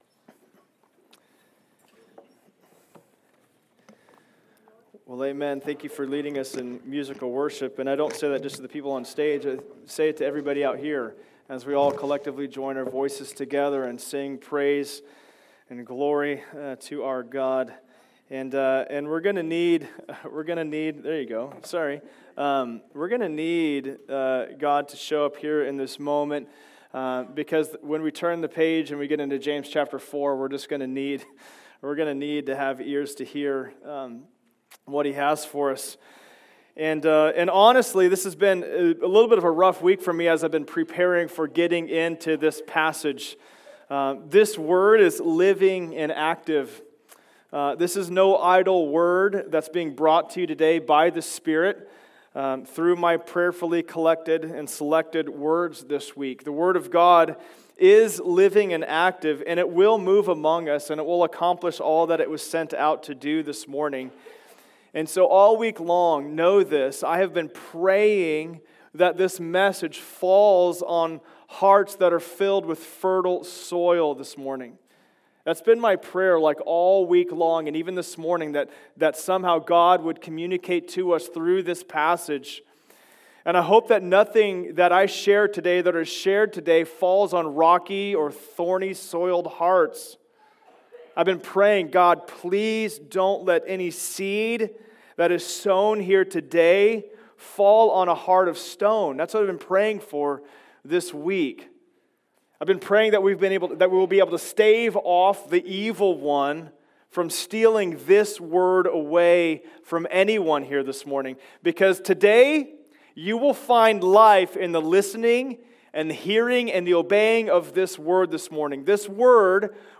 From Knowing to Doing Service Type: Sunday Service Download Files Notes « Godly Wisdom